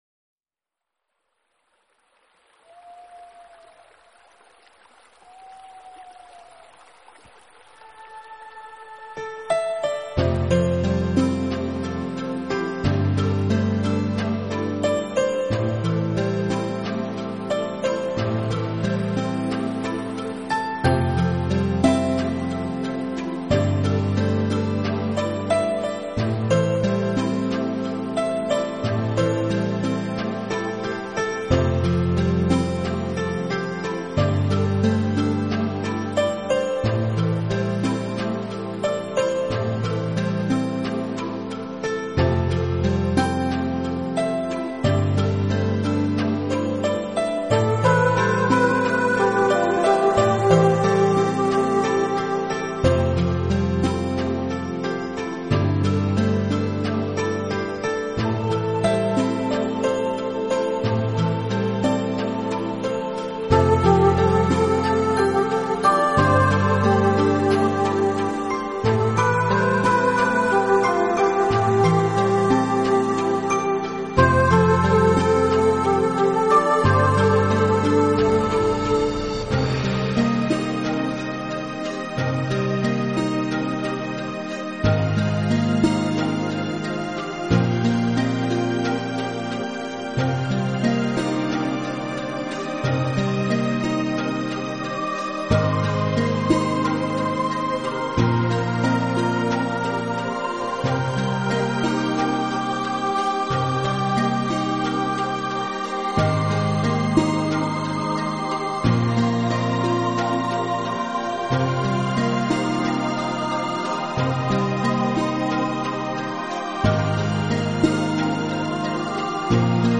【新世纪纯音乐】
温馨放松，恬静中略显伤感，抚慰疮痍，使你的